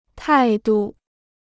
态度 (tài du): manner; bearing.